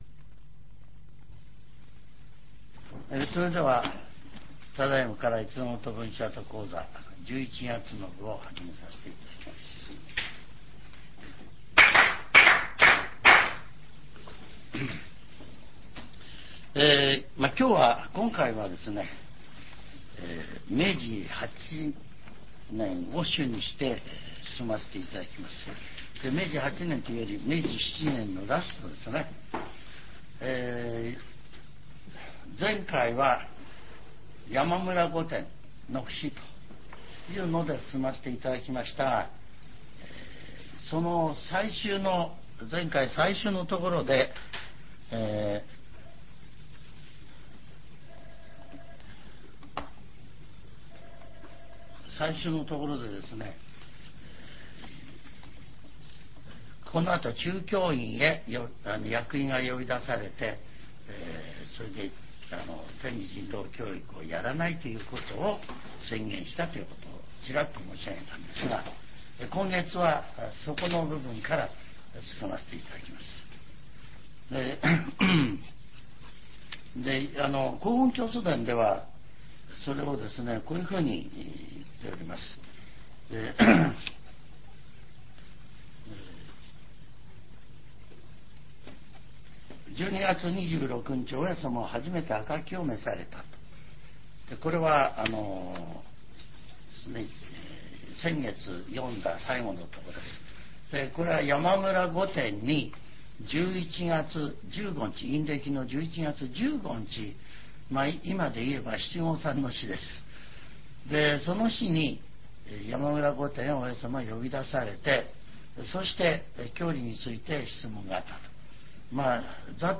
全70曲中27曲目 ジャンル: Speech